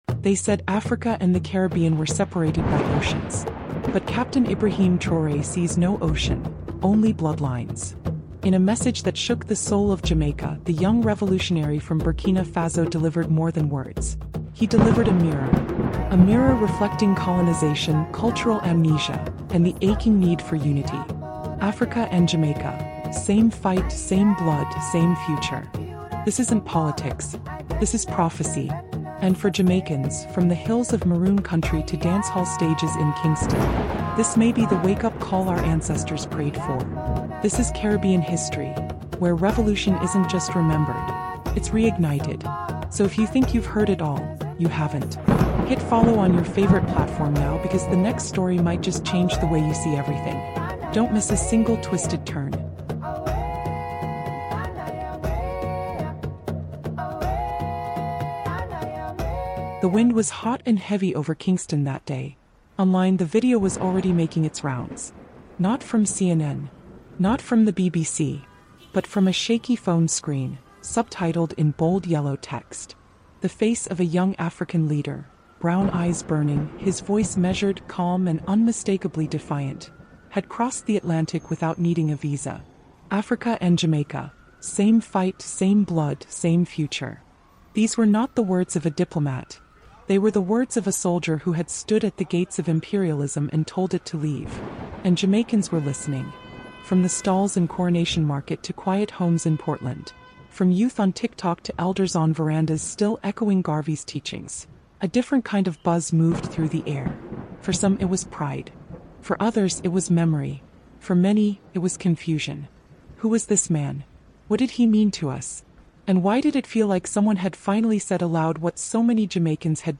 This powerful Caribbean History audiobook documentary unpacks the cultural impact of Captain Ibrahim Traoré’s message through the lens of black history, Caribbean music, and Pan-African resistance.